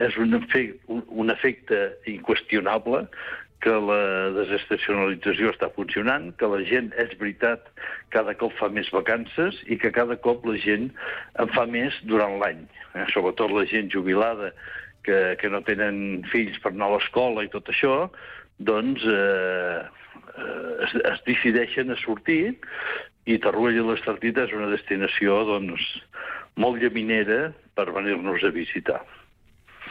Entrevistes SupermatíSupermatí
En una entrevista al programa Supermatí de Ràdio Capital i Ràdio Palafrugell, l’alcalde de Torroella de Montgrí i l’Estartit, Jordi Colomí, ha fet un balanç de la situació actual del municipi.